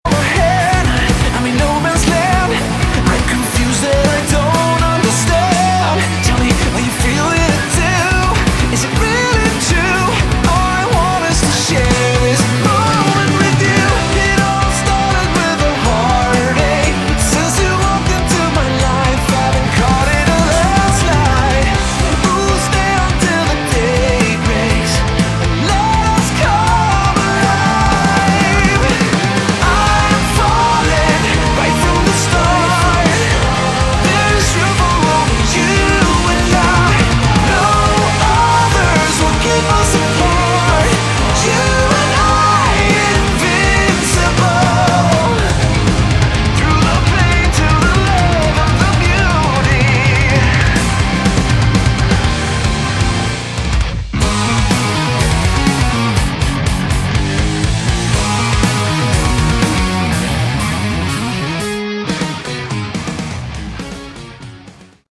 Category: Progressive Metal
guitar
bass
vocals
keyboards
drums